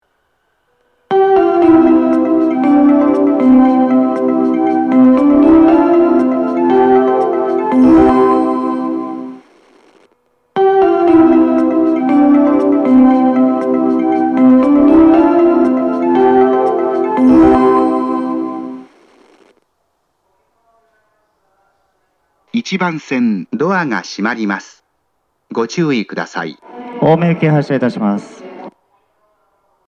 当駅は収録環境が悪く、なかなかメロディーを綺麗に収録できません。
発車メロディー
2コーラスです。